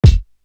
Pointer Kick.wav